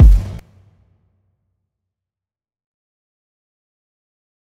TM88 RoomKick.wav